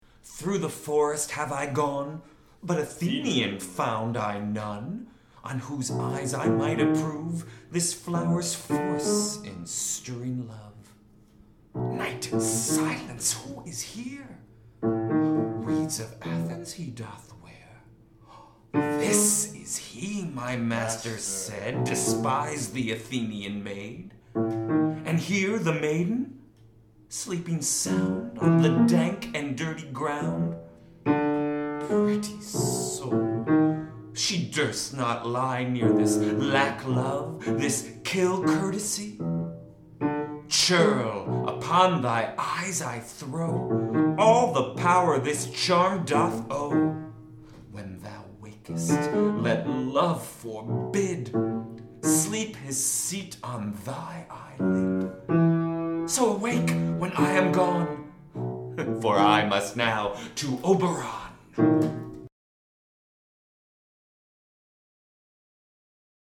vox
piano